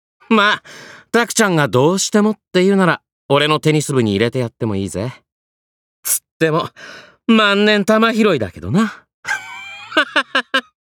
cha12kusaka_voice_sample.mp3